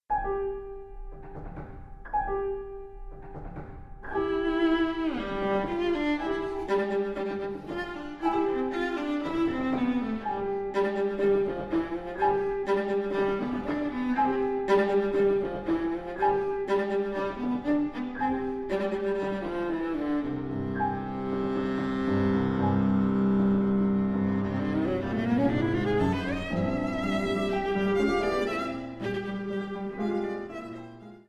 performs 20 of their viola transcriptions.